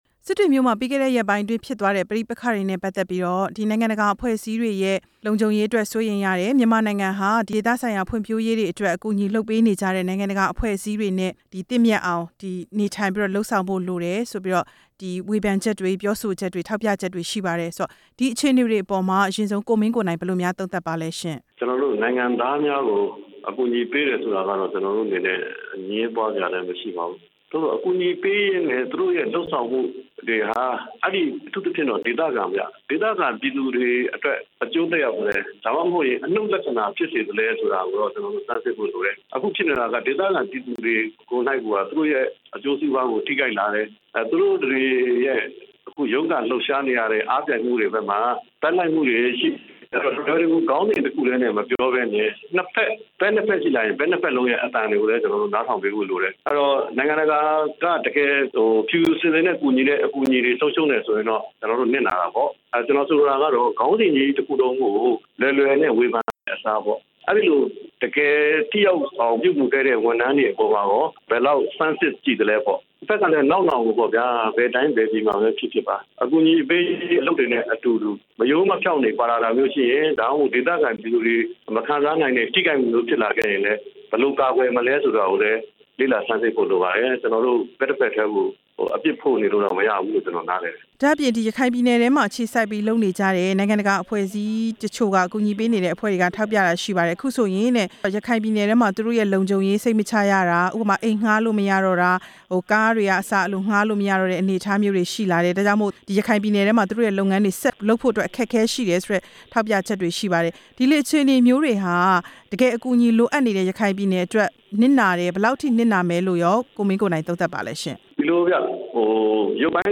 သန်ခေါင်းစာရင်းနဲ့ လူမျိုးရေးပြဿနာကိစ္စ ကိုမင်းကိုနိုင်နဲ့ မေးမြန်းချက်